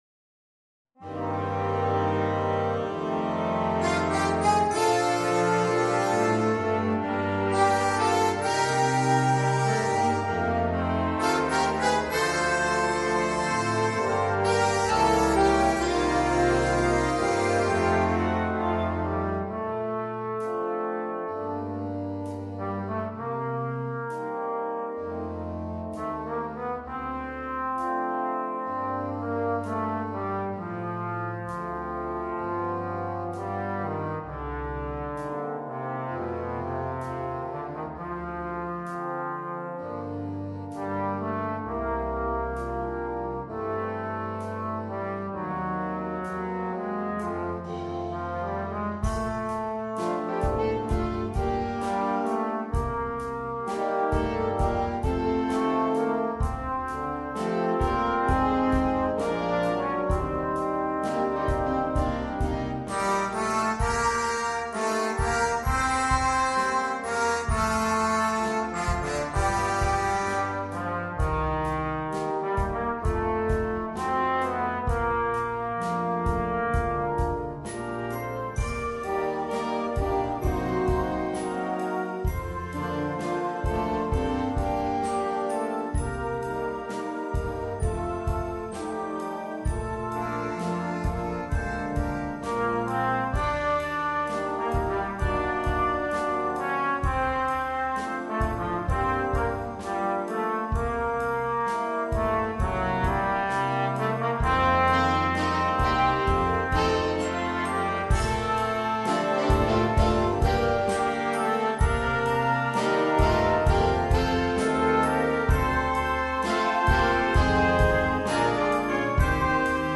per trombone (o sax tenore ) e banda